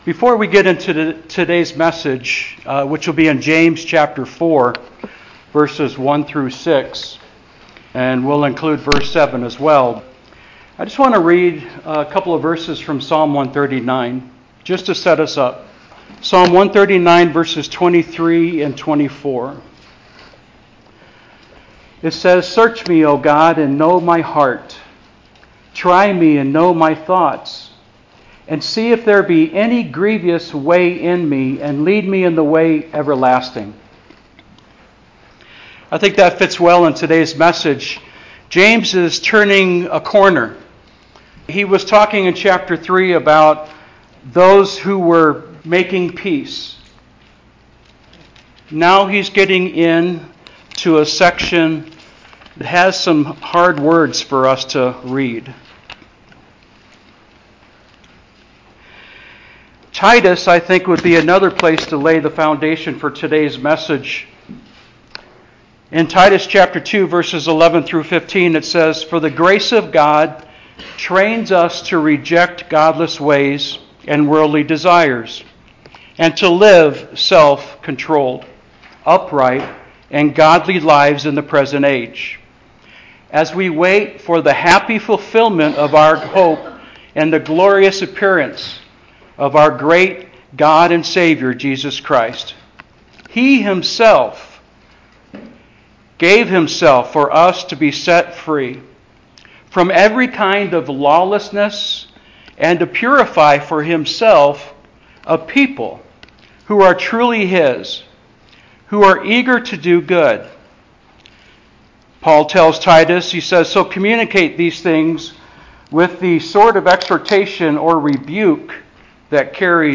Sermon Title: Love God, Hate the World